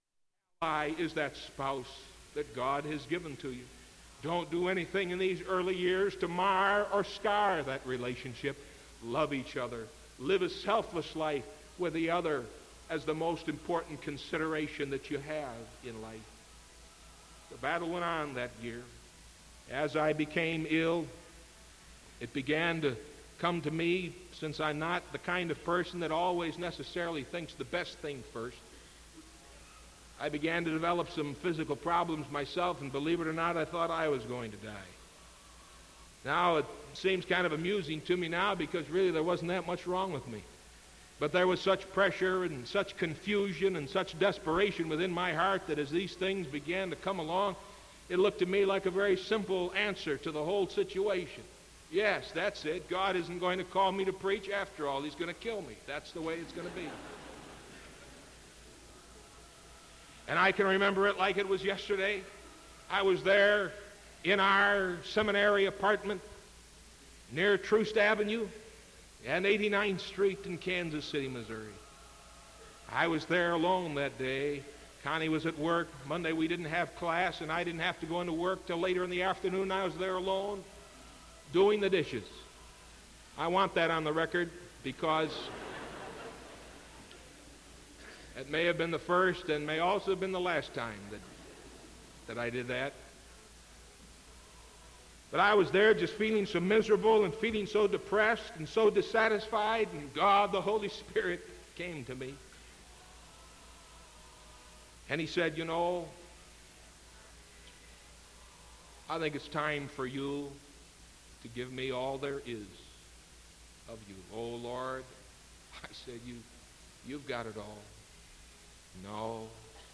Sermon April 6th 1975 PM